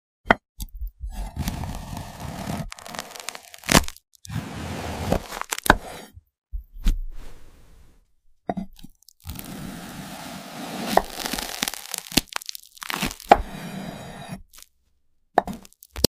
Each slice reveals a soft fruity layer, classic in Indonesian & Singapore street ice cream stalls. Simple and timeless — the Vanilla Ice Cream Block, sliced in 8K ASMR detail. The smooth, frozen white surface cracks open with satisfying crunch, revealing creamy softness inside.